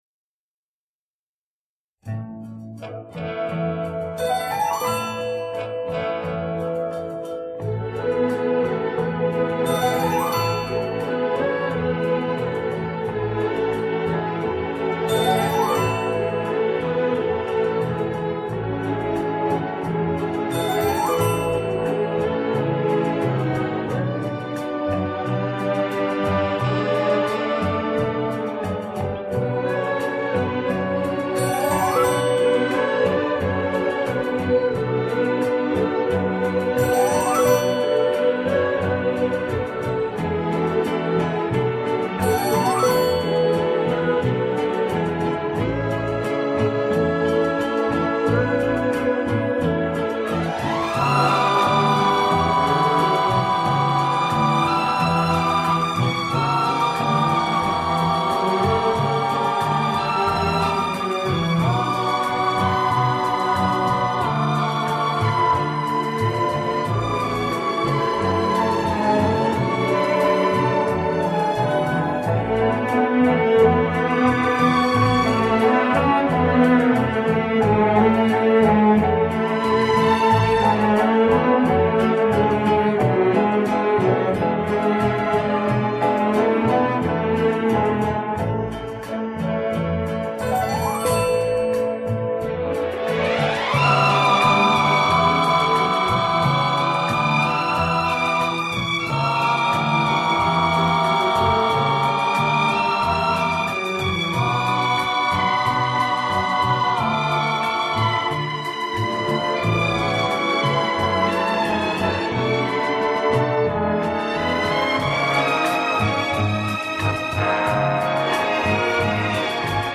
Genre:Instrumental,Easy Listening